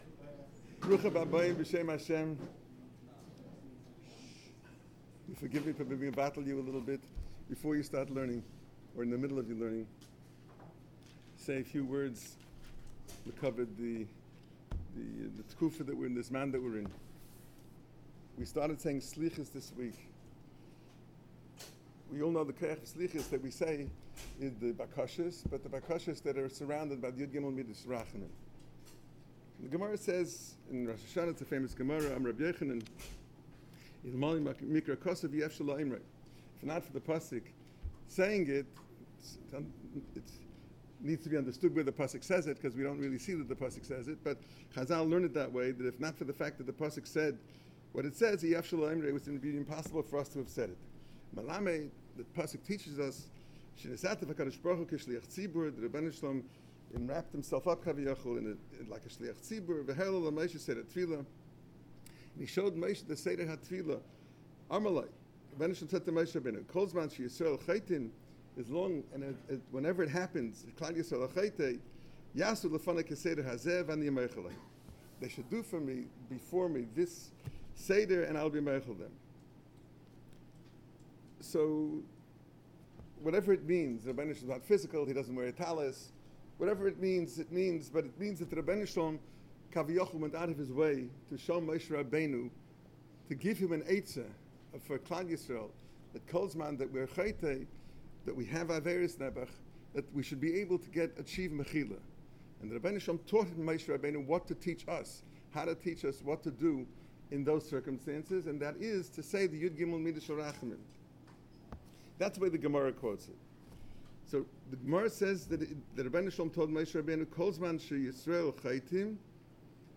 Alumni Events, Special Lecture - Ner Israel Rabbinical College
On Labor Day, the second day of slichos, the Yeshiva held a Yarchei Kallah for three shuls: Bnai Jacob Shaarei Zion, Shomrei Emunah and Suburban Orthodox.